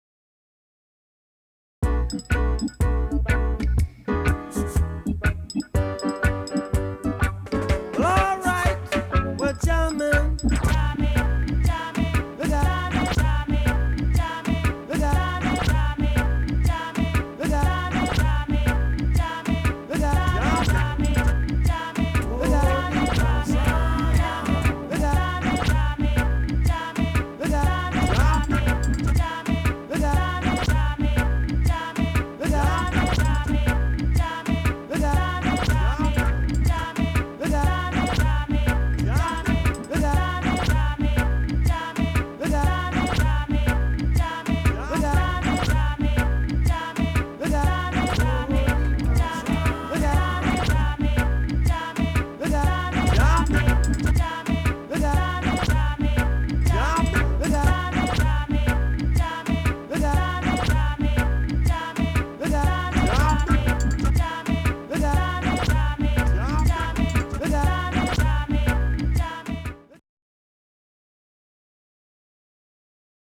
Creating music with code